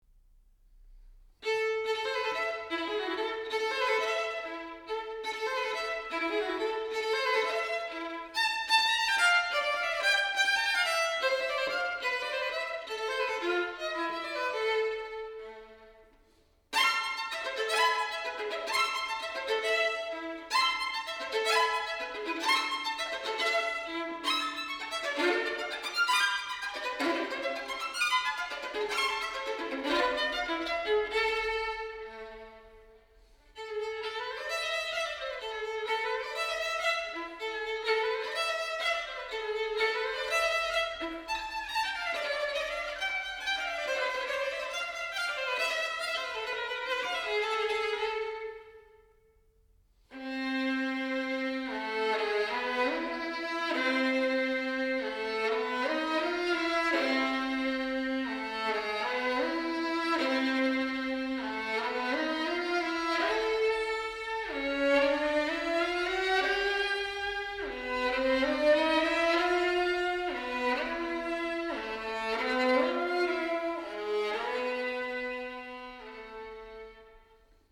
小提琴
古典音樂、發燒音樂
PER VIOLINO SOLO
• TEMA QUASI PRESTO VARIAZIONE FINALE IN LA MIN.